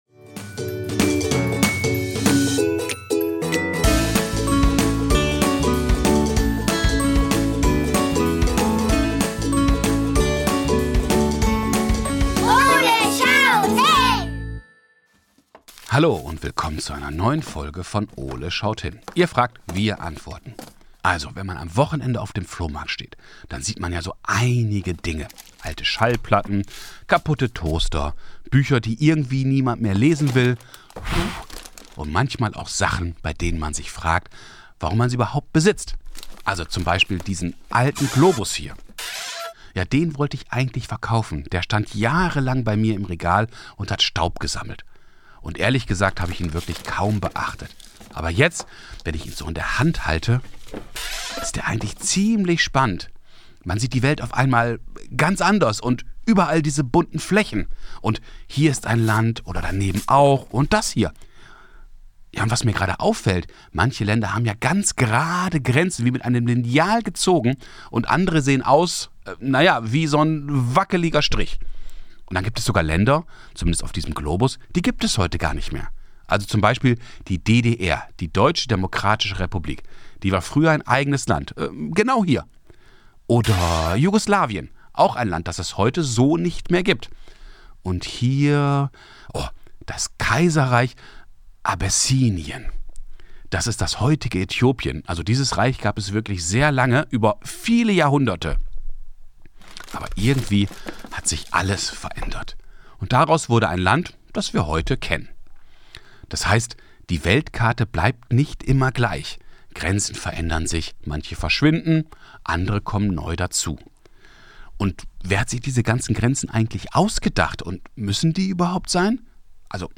Die Journalistin und Fernsehmoderatorin Shakuntala Banerjee erklärt im Kinderpodcast „Ole schaut hin“, wie Länder entstehen.